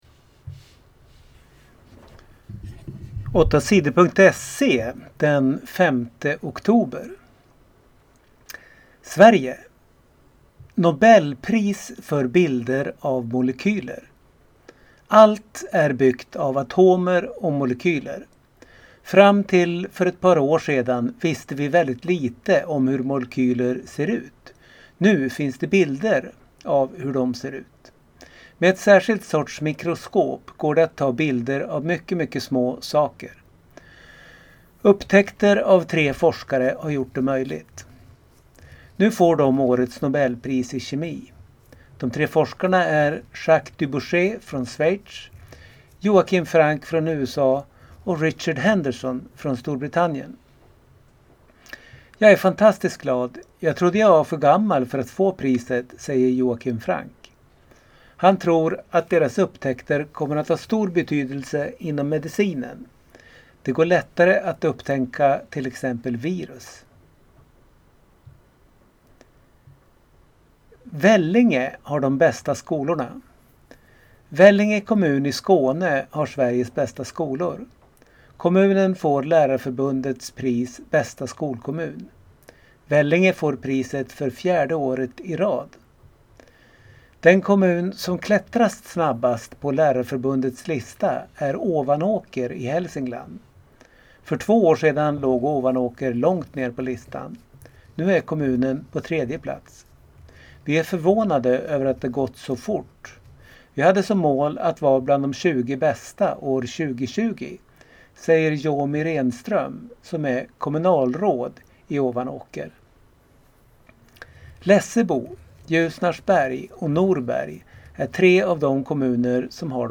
Lyssna på nyheter från torsdagen den 5 oktober